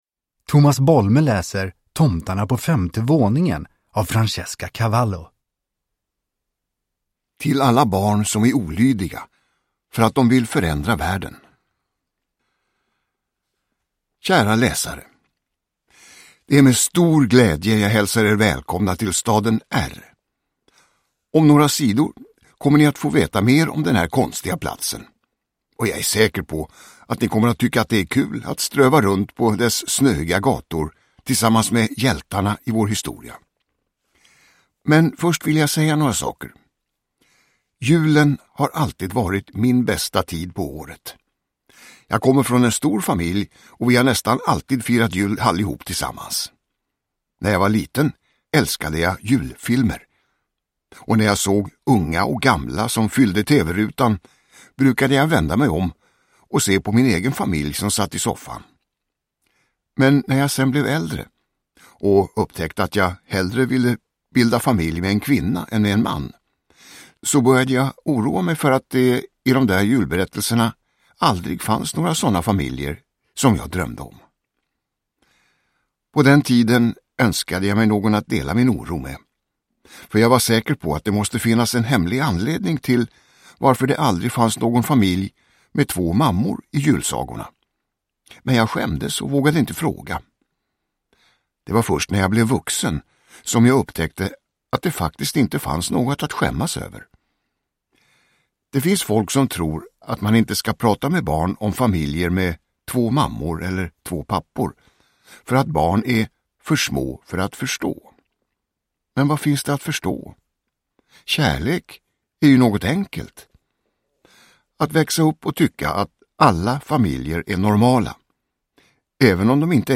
Tomtarna på femte våningen – Ljudbok – Laddas ner
Uppläsare: Tomas Bolme